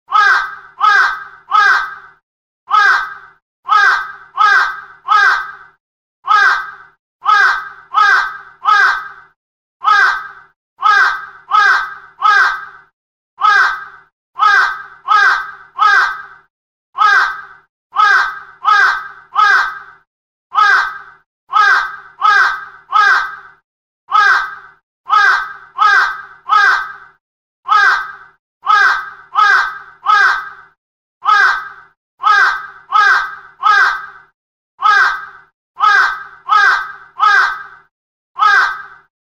iPhoneとandroidのスマートフォン用烏（カラス）の鳴き声着信音を配信！
かぁかぁかぁかぁ・・・。
烏（カラス）の鳴き声 着信音